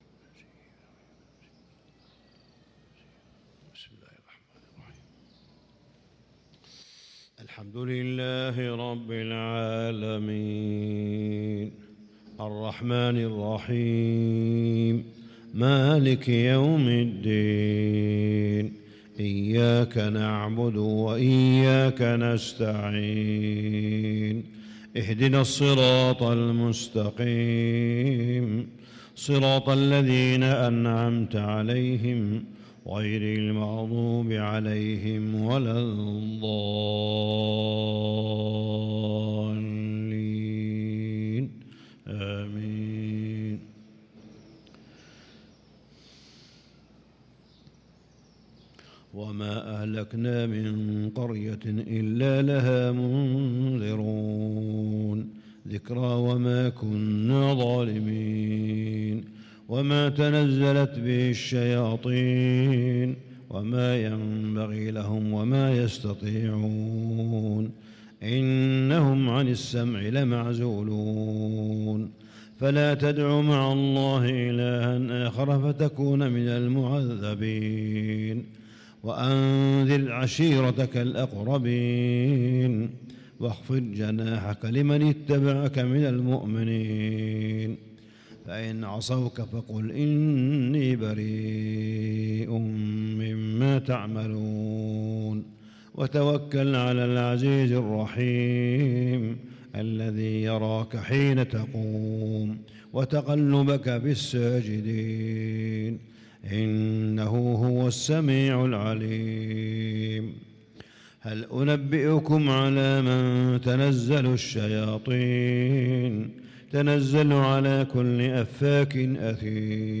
صلاة الفجر للقارئ صالح بن حميد 25 رمضان 1445 هـ